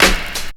INSTCLAP11-R.wav